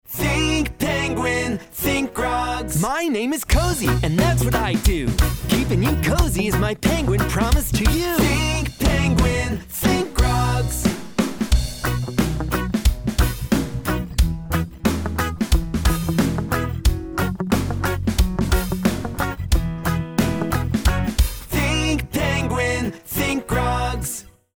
Cozy_30_island_chill.mp3